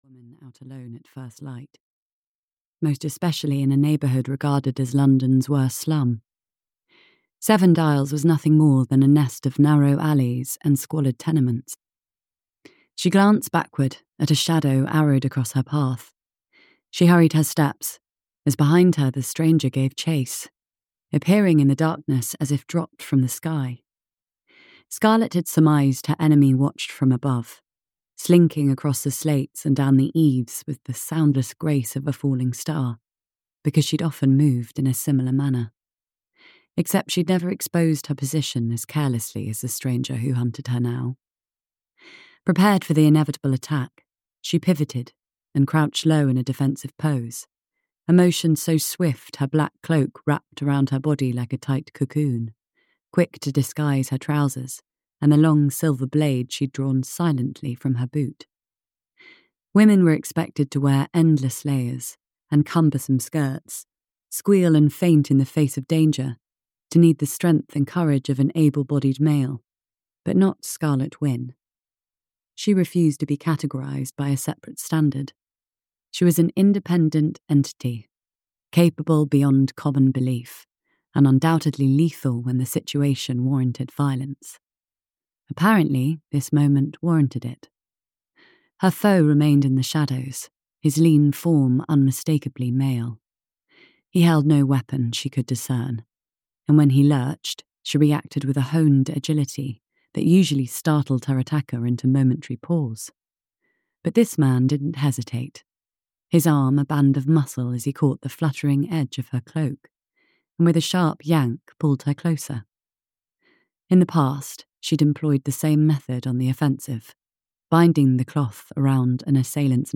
Audio knihaDuchess If You Dare: A spicy, suspense-filled, regency romance (EN)
Ukázka z knihy